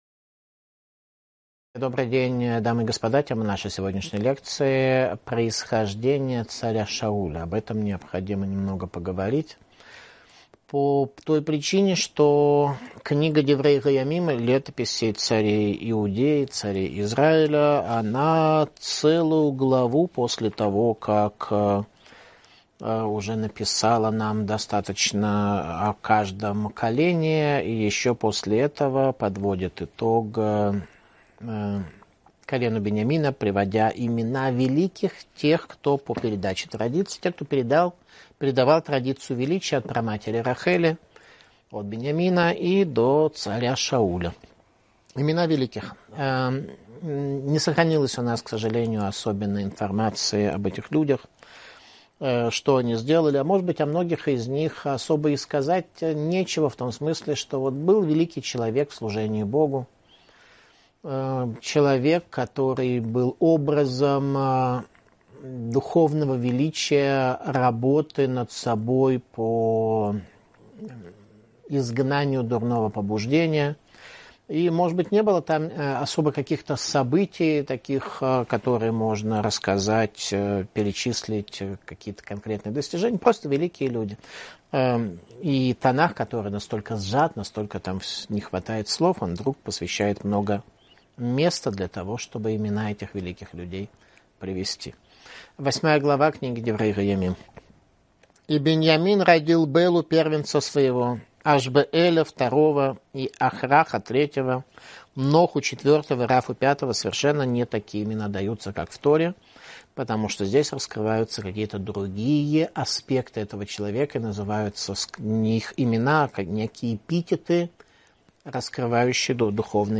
Иудаизм и евреи Видео Видеоуроки Танах Диврей Аямим — Летописи царей Израиля 59.